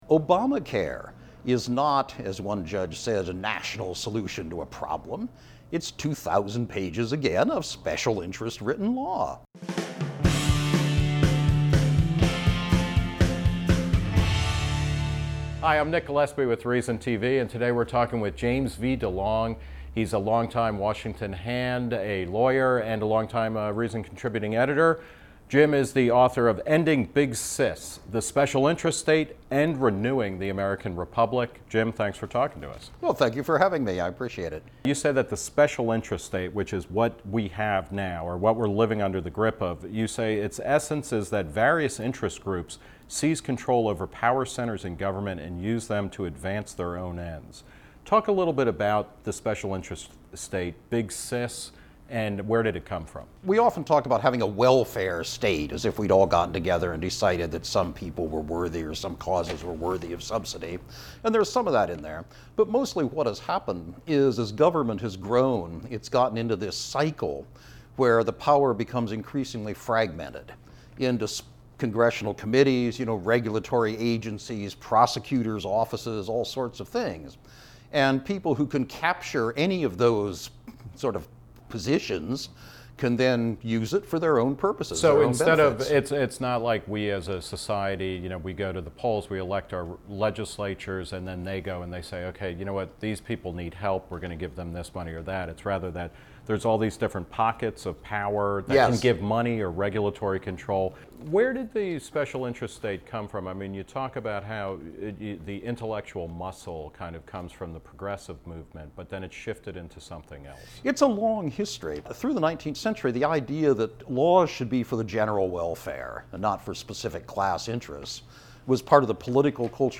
Interview by Nick Gillespie.